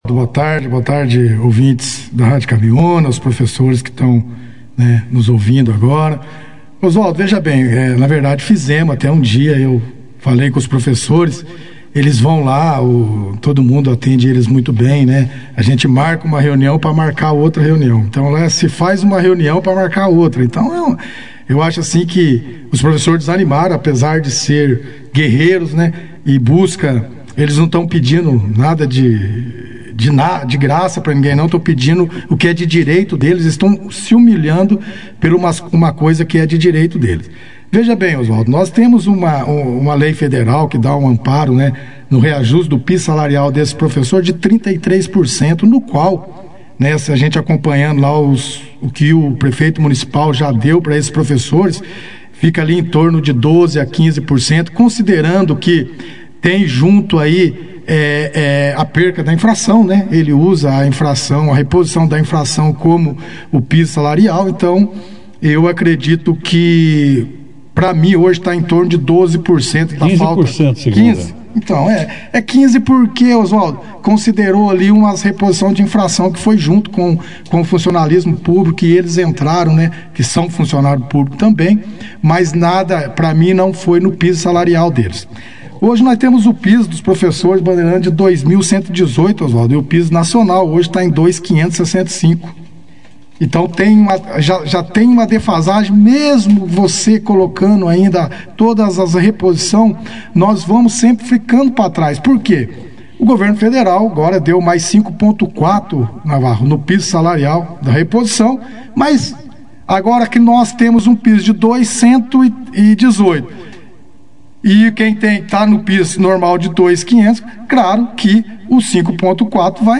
O vereador Carlão Demicio participou, nesta terça-feira, 23 de janeiro, da 2ª edição do Jornal Operação Cidade. Durante a entrevista, o edil fez cobranças à administração municipal em relação à morosidade na condução de algumas demandas, principalmente nas obras em execução, e também abordou a situação dos professores da rede pública municipal.